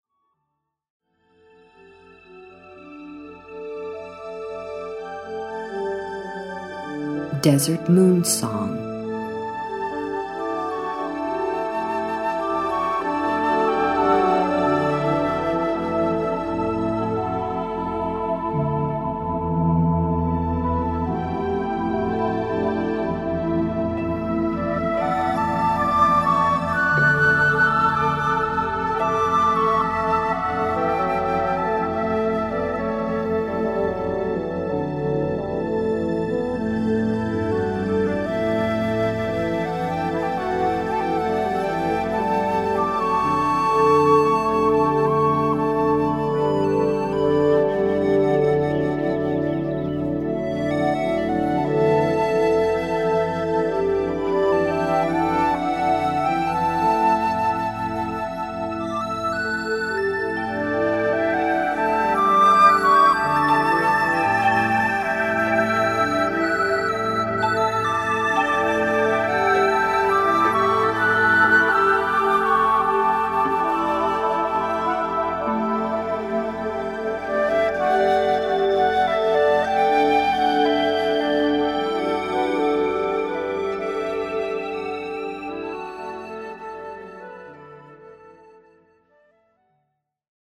Relaxace, Meditace, Relaxační a Meditační hudba
Verbální vedení: Neverbální